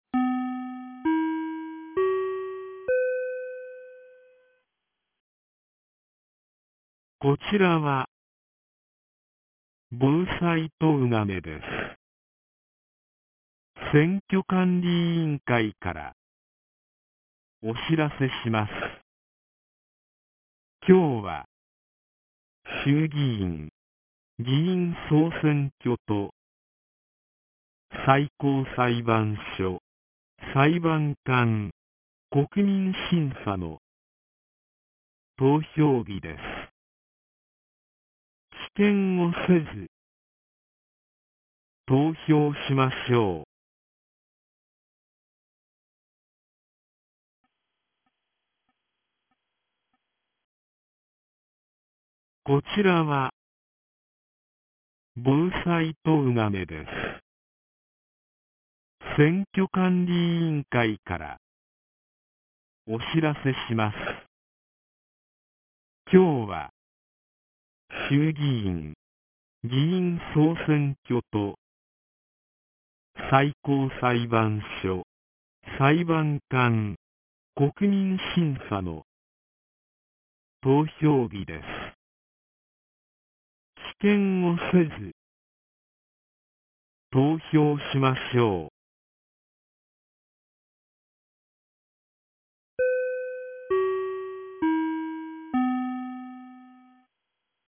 2024年10月27日 09時01分に、東金市より防災行政無線の放送を行いました。